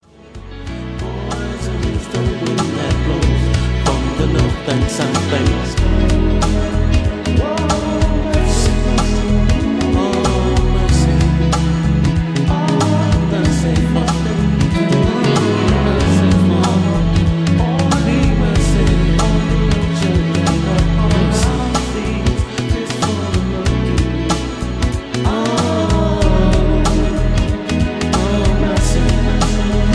karaoke mp3s , backing tracks